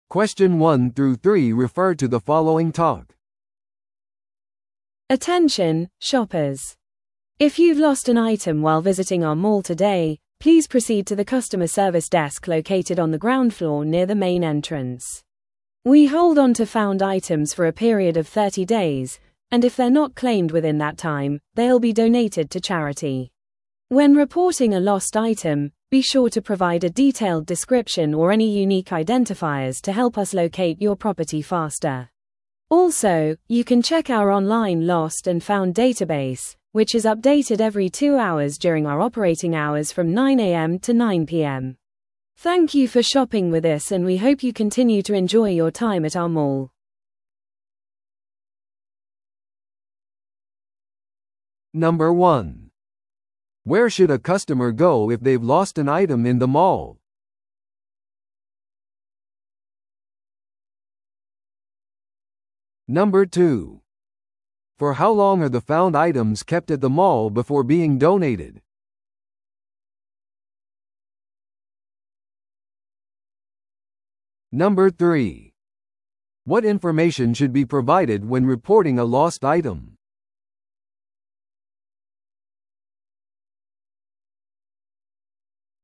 TOEICⓇ対策 Part 4｜ショッピングモールの遺失物対応 – 音声付き No.034